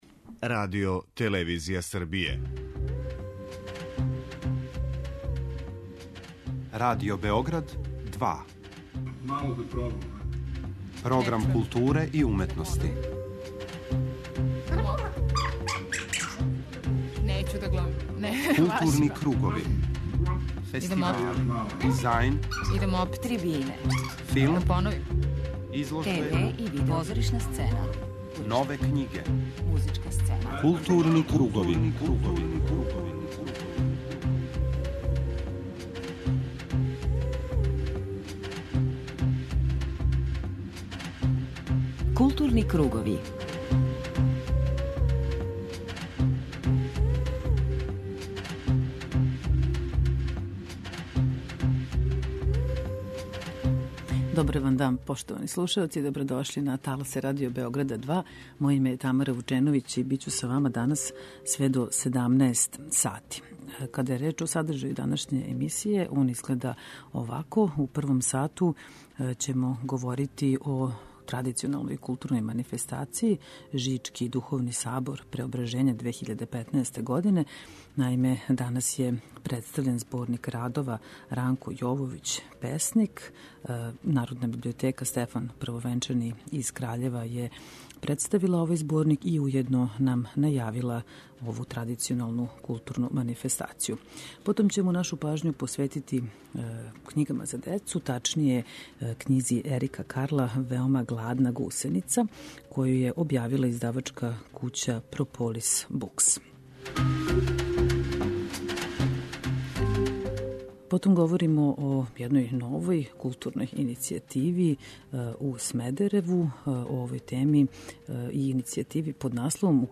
преузми : 52.20 MB Културни кругови Autor: Група аутора Централна културно-уметничка емисија Радио Београда 2.